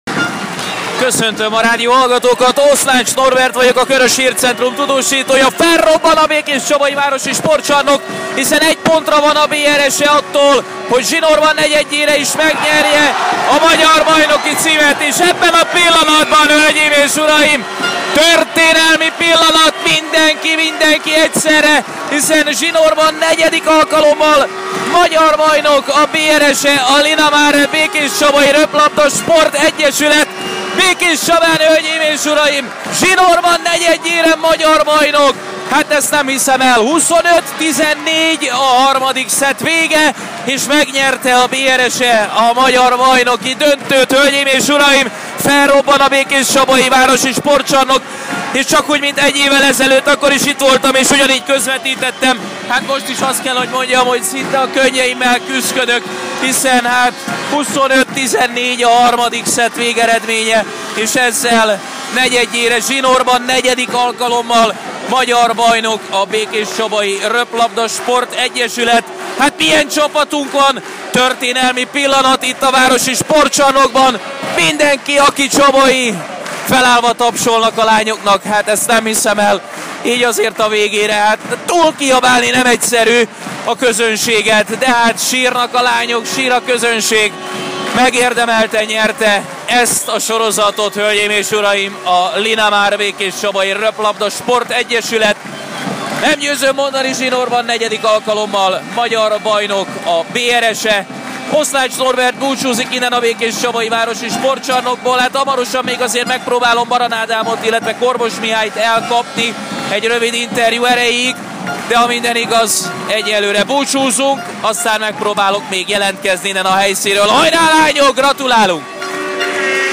bejelentkezése a győzelem pillanatában itt meghallgatható.